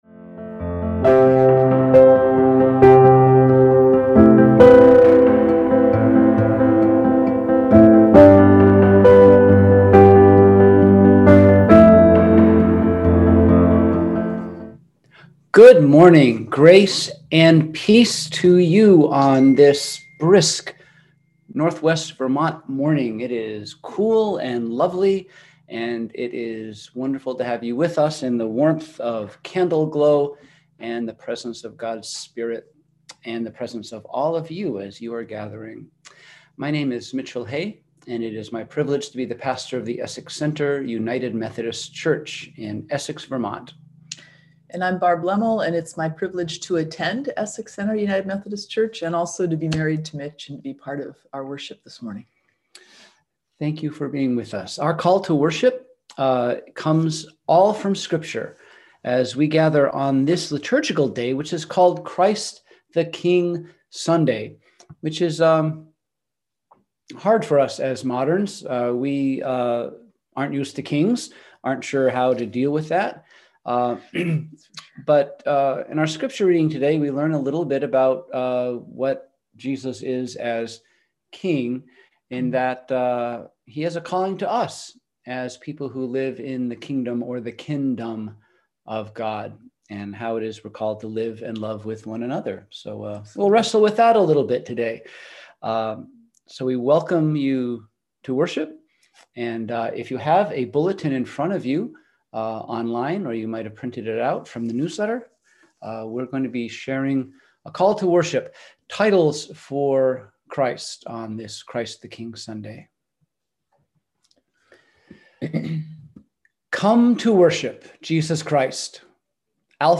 We held virtual worship on Sunday, November 22, 2020 at 10:00am.